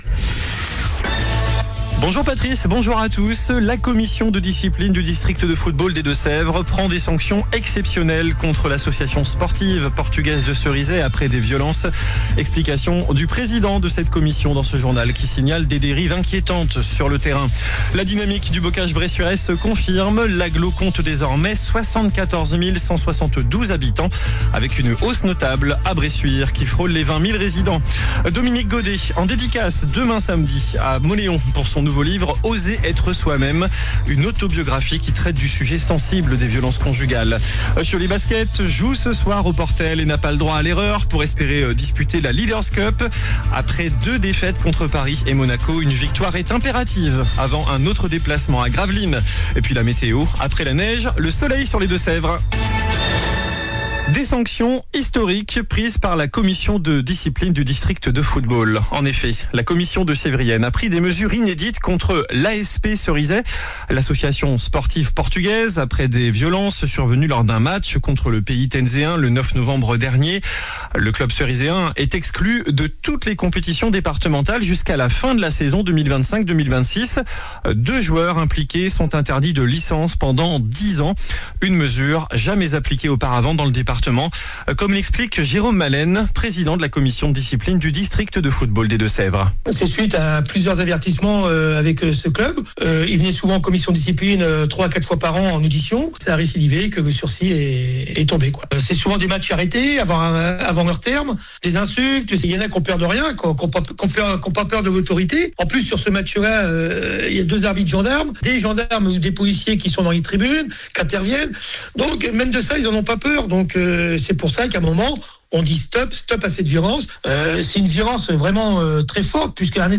JOURNAL DU VENDREDI 26 DECEMBRE ( MIDI )